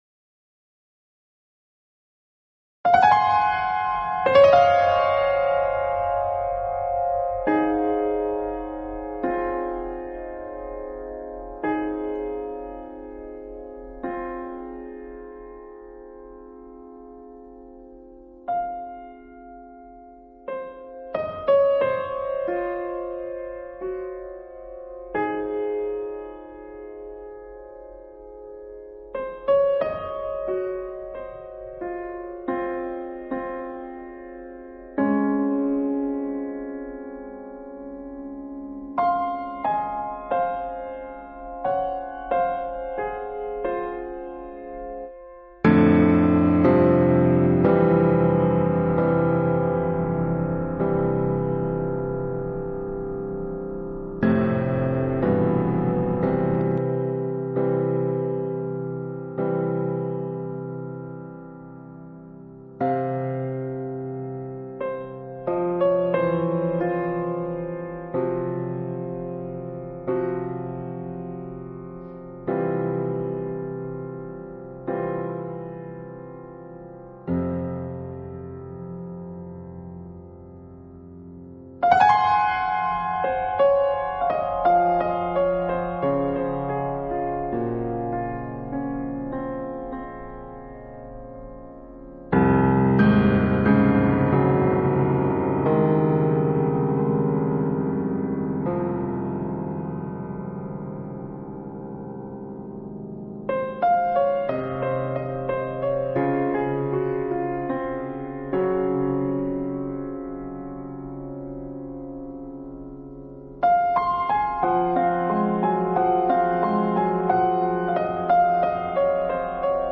The first movement is primarily expository, and intended as primordial, mysterious, unexpected and nondescript. Its tempo and meter are composed very deliberately to have a vague sense of time, and played with a fair amount of rubato.